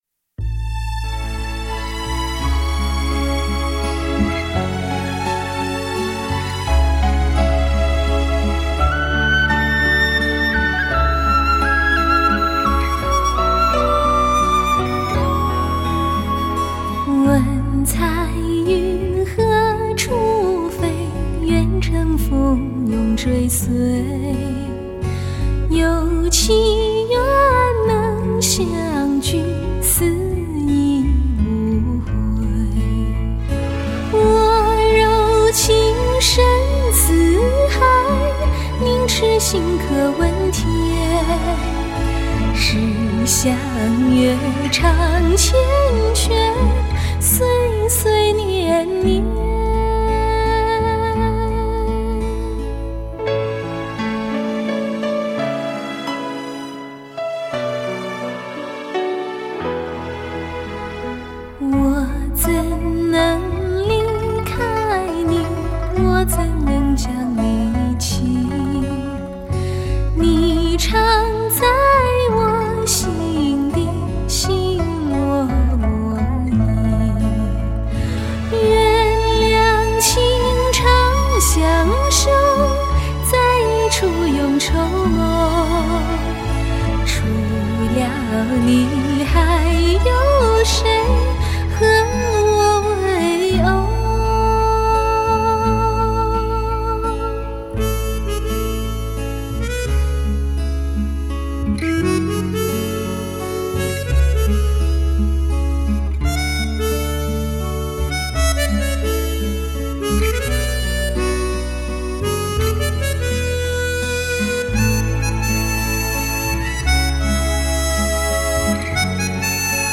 更自然，更精准，更多音乐细节。